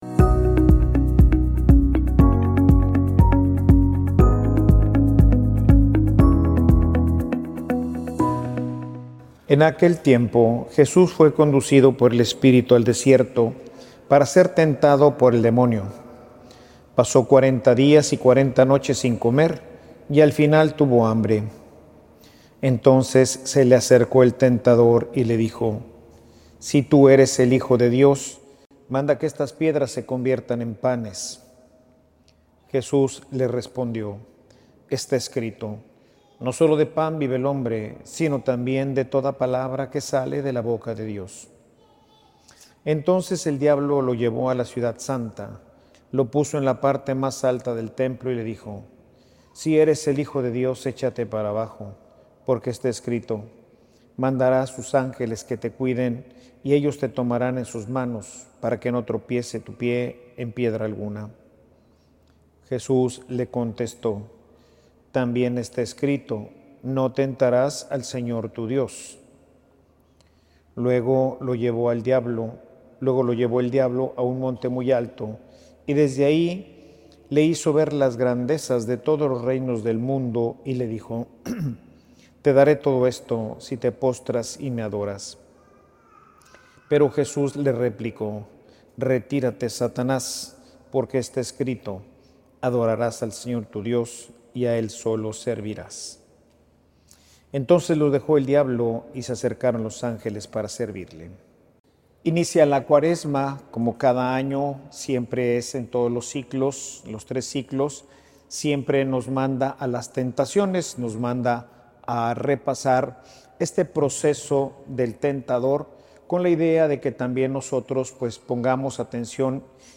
Homilia_Confia_en_la_palabra_de_dios.mp3